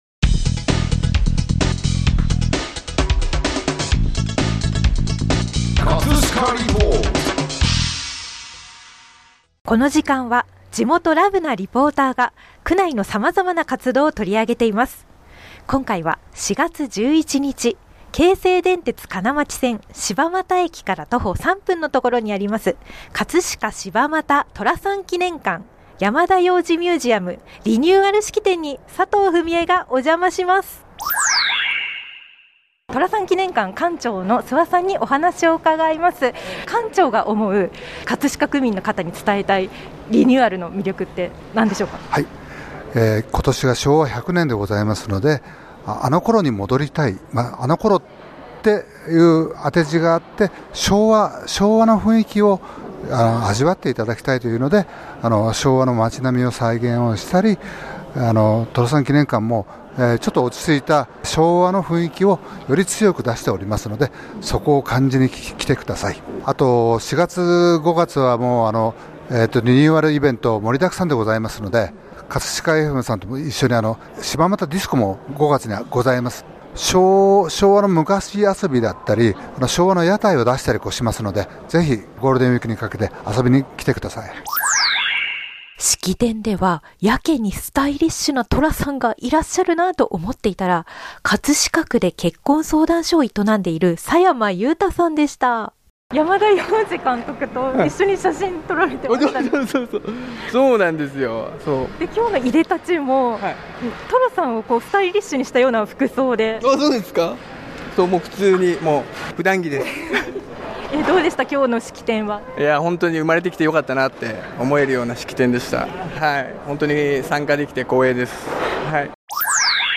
というわけで、翌々日の4/13(日)に再度お邪魔しお客さんたちの反応をインタビュー♪
この日は冷たい雨が降る寒い日でしたが、写真を撮ってホクホクの女性3人組の皆さんをはじめ、寅さん記念館を楽しむ方々のお話を伺うことができましたよ♪
▼リポート音声